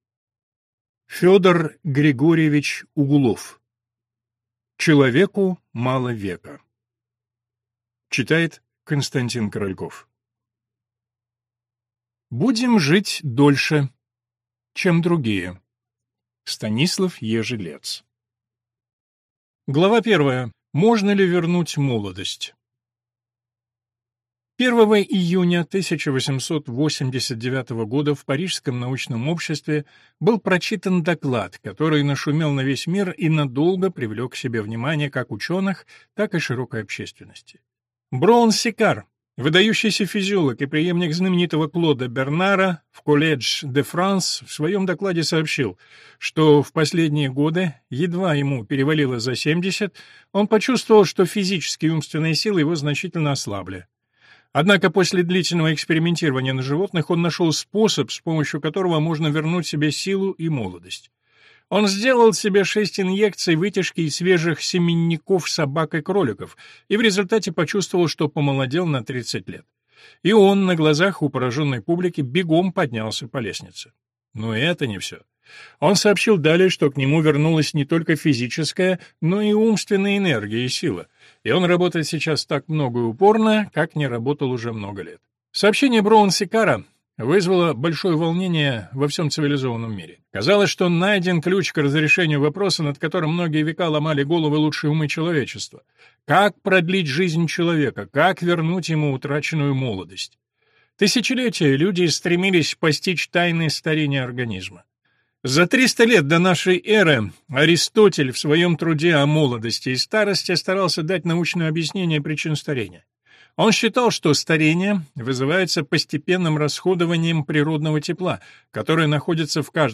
Аудиокнига Человеку мало века | Библиотека аудиокниг